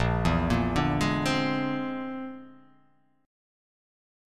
A#9b5 chord